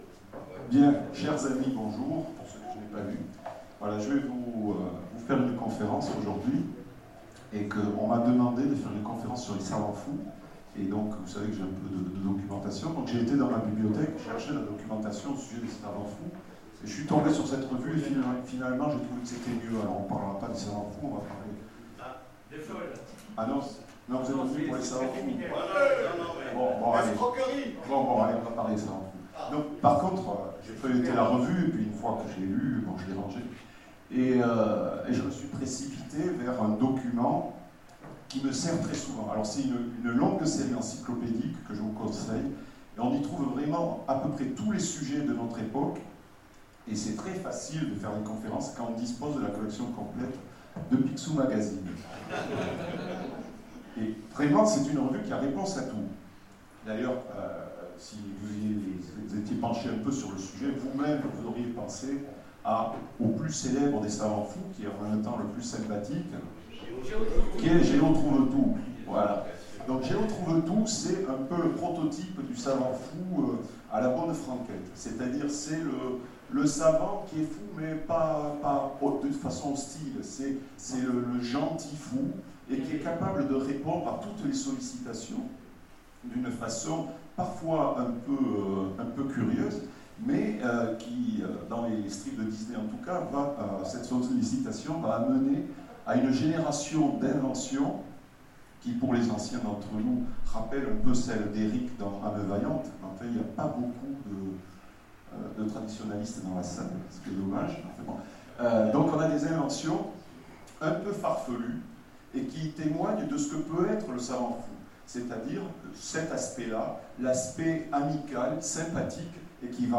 Convention SF 2016 : Conférence Savants fous